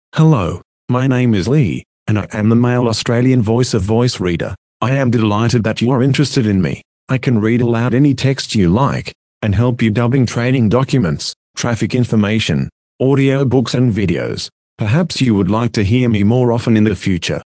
Voice Reader Home 22 English (Australian) - Male voice [Lee]
Voice Reader Home 22 ist die Sprachausgabe, mit verbesserten, verblüffend natürlich klingenden Stimmen für private Anwender.